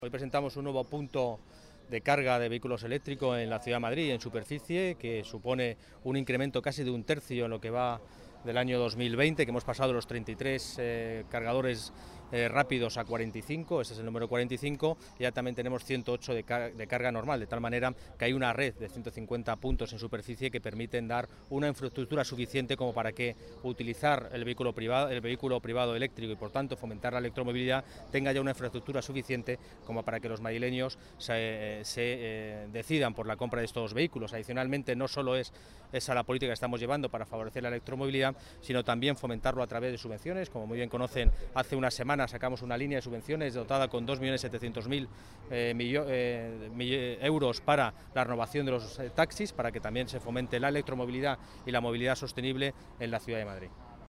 Nueva ventana:Declaraciones del delegado de Medio Ambiente y Movilidad, Borja Carabante
AUDIO CARABANTE DECLARACIONES PUNTOS DE RECARGA.mp3